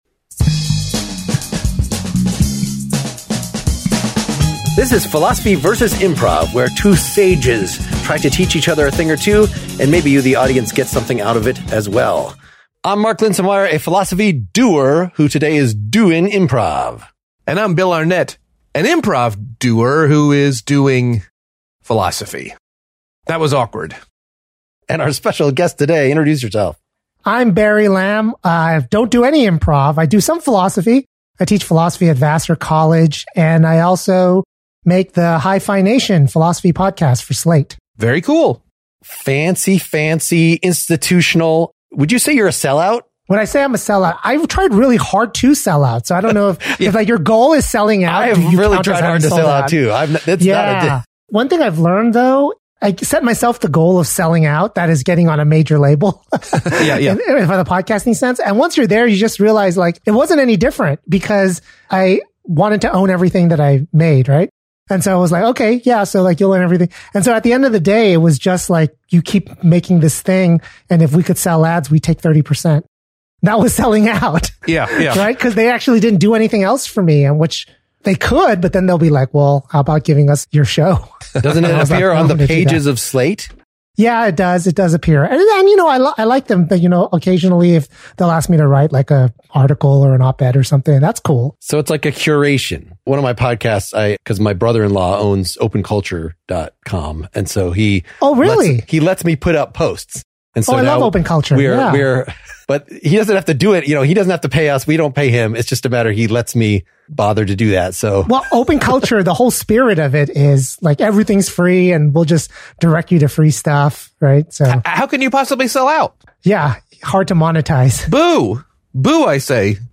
So we talk about that notion that Hollywood has had so much fun with lately, and then we have fun with it in a couple of variations on a classic diner scene.